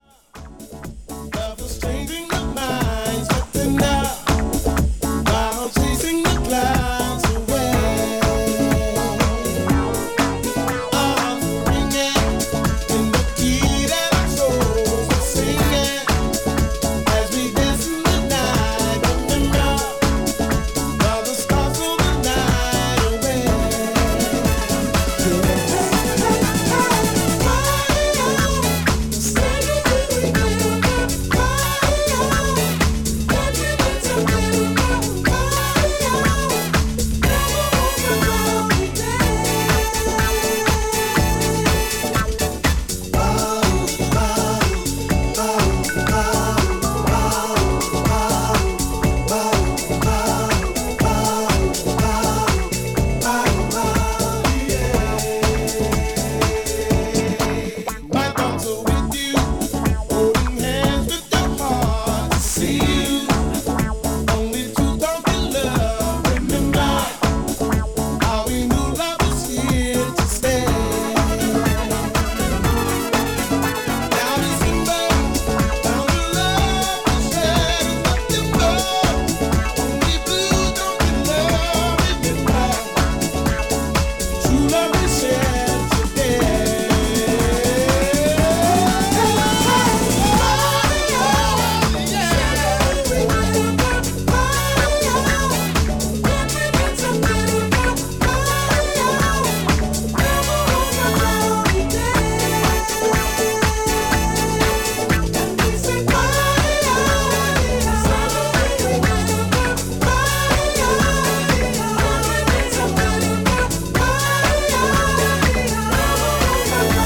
STYLE Soul